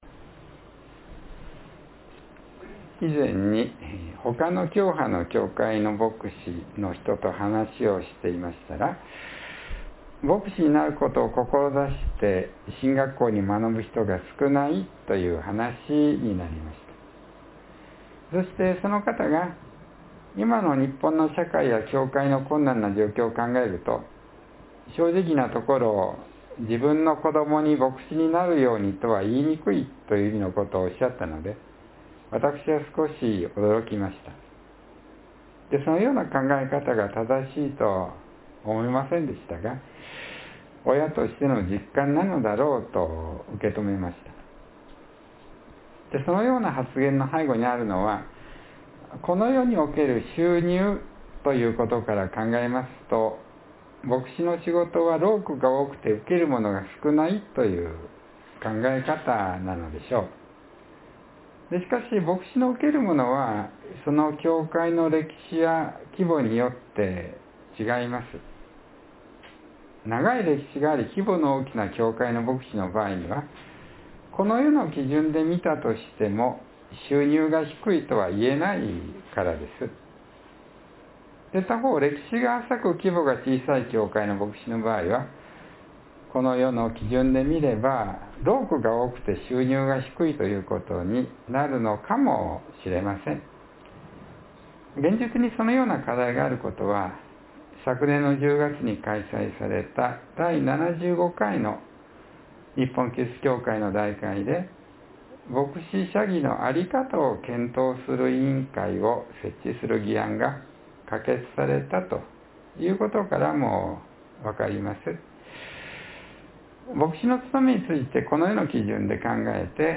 （1月18日の説教より）